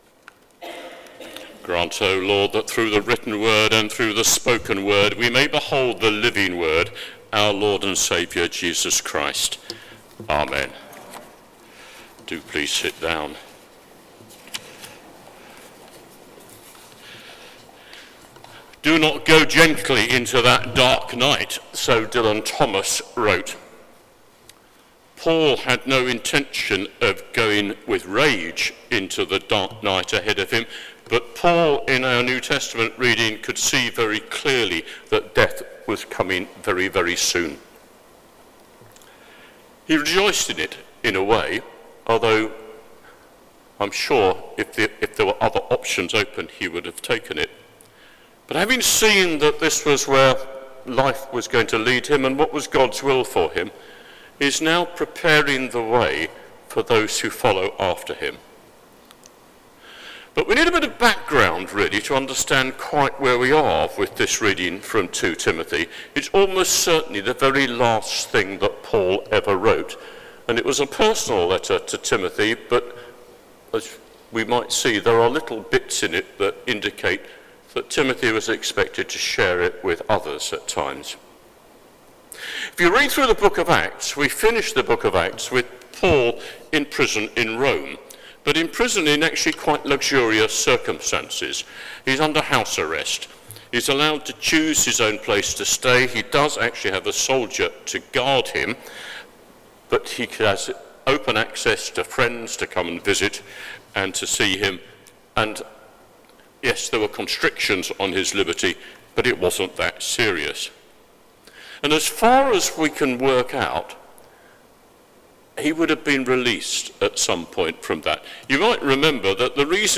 Sermon: Keep the faith | St Paul + St Stephen Gloucester